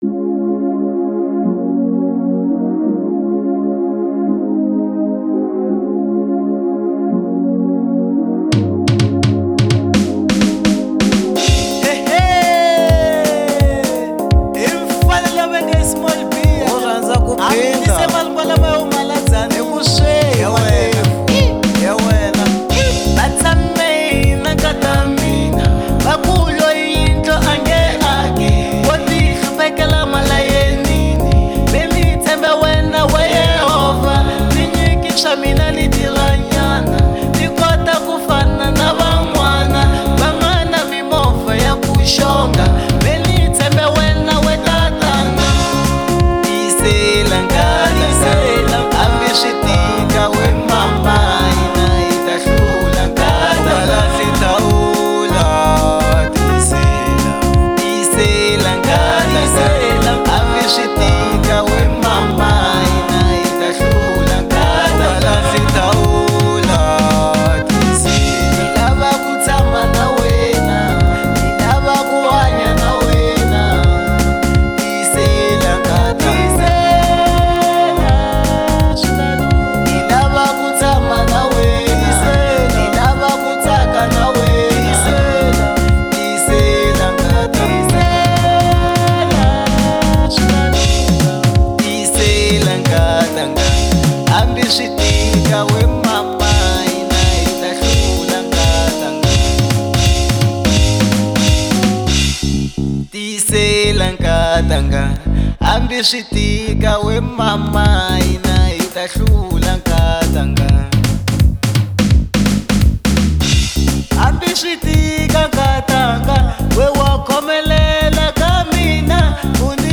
03:05 Genre : African Disco Size